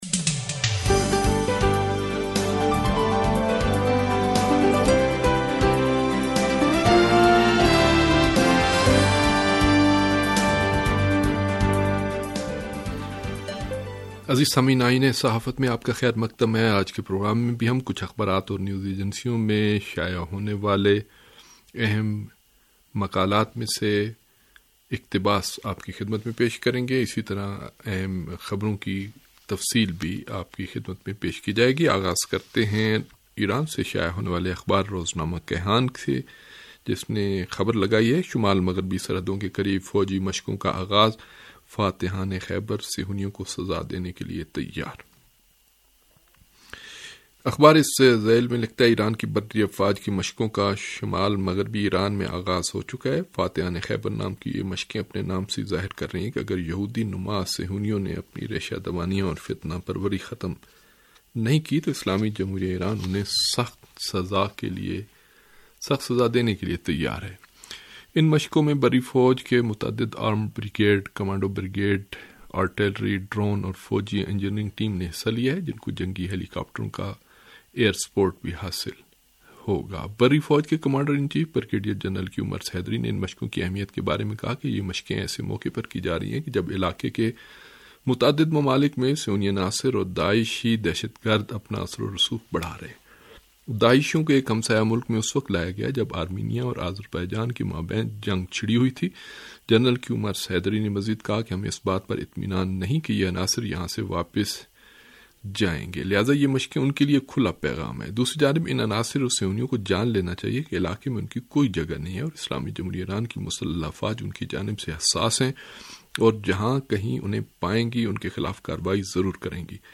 ریڈیو تہران کا اخبارات کے جائزے پرمبنی پروگرام آئینہ صحافت